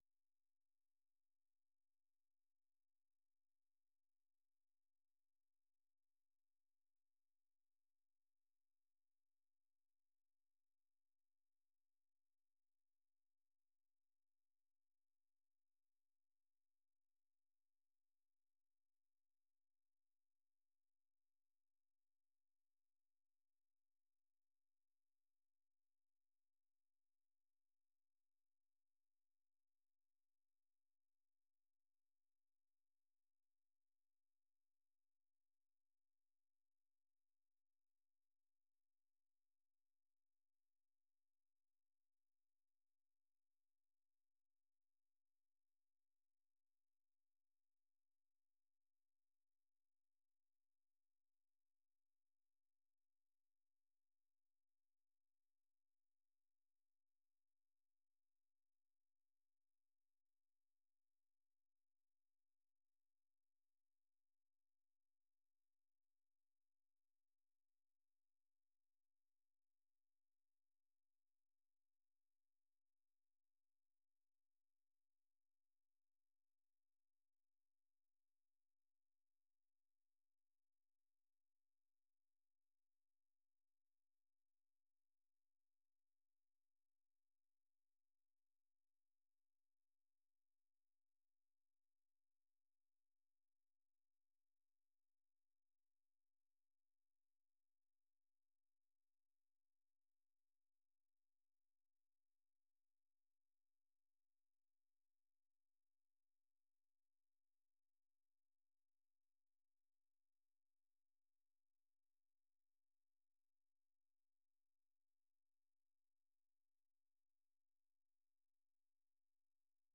The URL has been copied to your clipboard 페이스북으로 공유하기 트위터로 공유하기 No media source currently available 0:00 0:59:59 0:00 생방송 여기는 워싱턴입니다 생방송 여기는 워싱턴입니다 저녁 공유 생방송 여기는 워싱턴입니다 저녁 share 세계 뉴스와 함께 미국의 모든 것을 소개하는 '생방송 여기는 워싱턴입니다', 저녁 방송입니다.